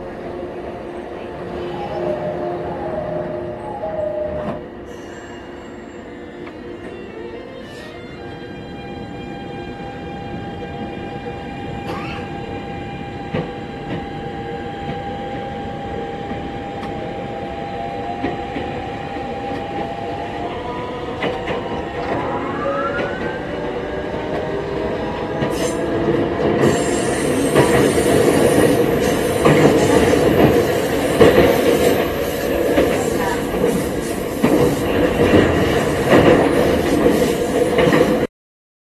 日本で導入されたSIEMENSのGTO-VVVFは非同期モードの出だしが音階的な変化になるのが特徴ですが、E501系の変わったところは、電制（回生？）が完全に停車するまで効くので停まるときには逆の音階まで聞こえてくるのがポイントです。
加速時、同期モードの第1段階目から第2段階目に変わるときにCI装置から何が作動するような「ウィーン」という音が出ます。
走行音（車両中央部）
収録区間：常磐線 三河島発車時